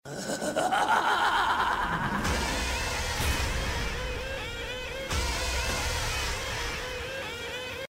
Звуки злобного смеха
Смех зловещего клоуна